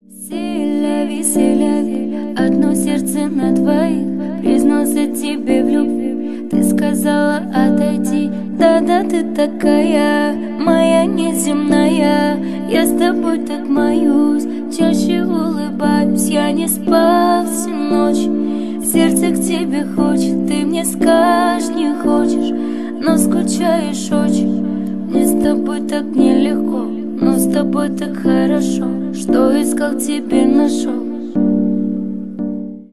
Поп Музыка # кавер # кавказские # спокойные